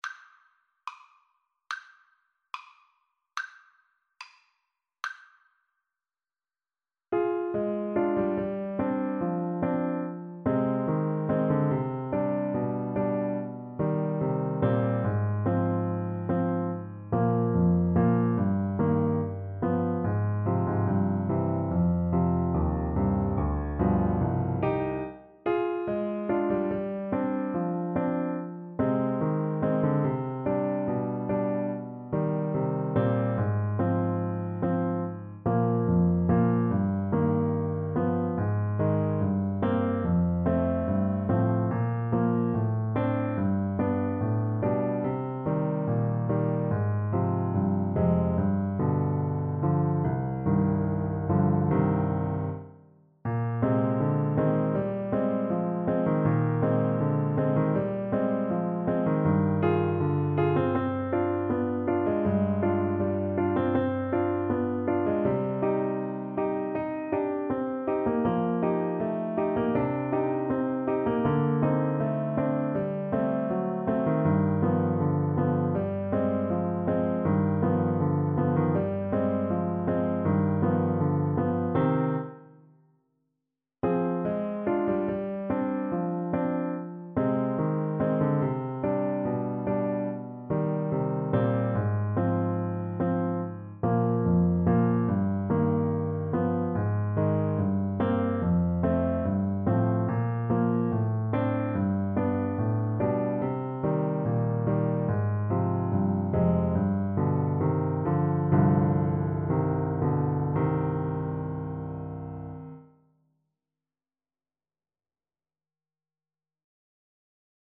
2/4 (View more 2/4 Music)
~ = 72 Andantino (View more music marked Andantino)
Classical (View more Classical Saxophone Music)